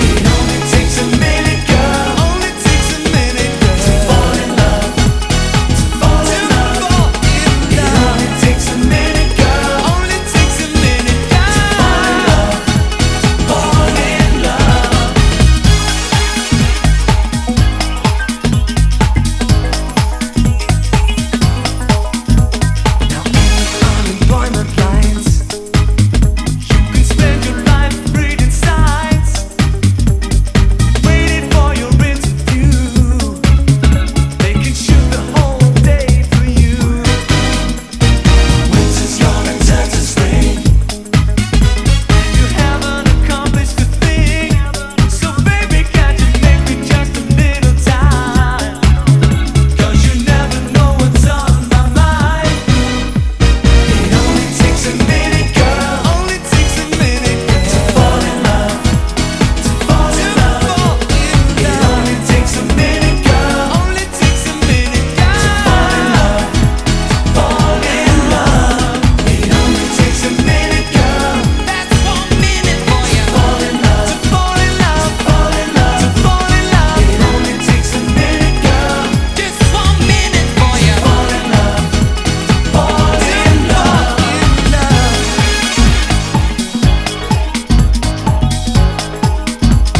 EASY LISTENING / VOCAL / FRENCH